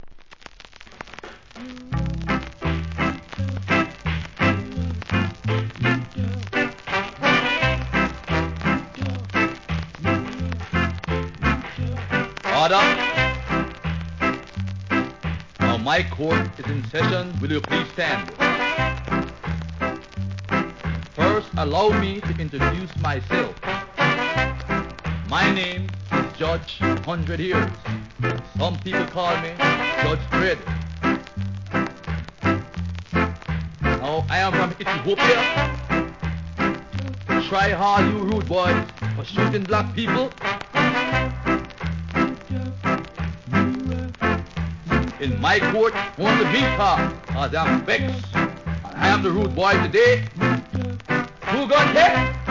ROCK STEADY